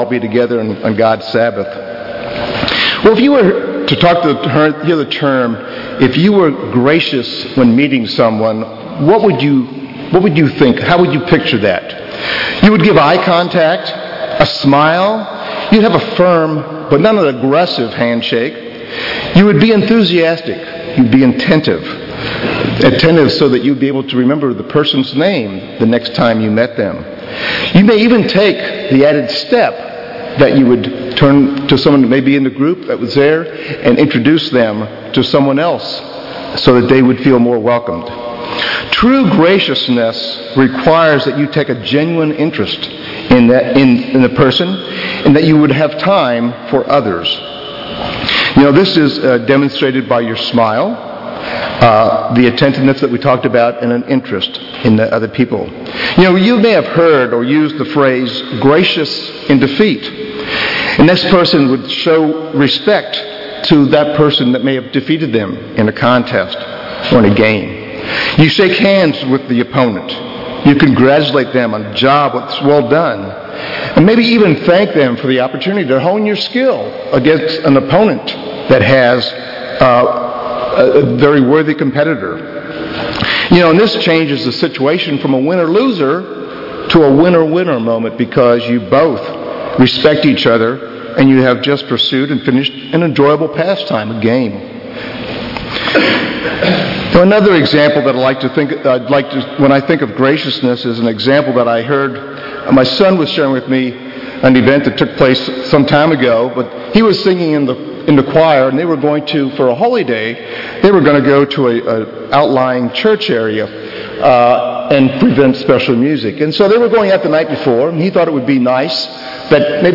split-sermon